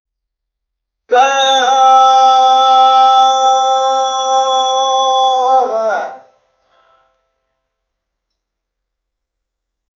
reading azan in mosque 0:47 Created Apr 15, 2025 4:06 AM Breaking fast sound, azan prayer 0:15 Created Mar 15, 2025 6:17 AM A guy from Pakistan do azan.
The maqam is Ajam 0:10 Created Jun 13, 2025 3:12 AM
a-guy-from-pakistan-do-dogll62m.wav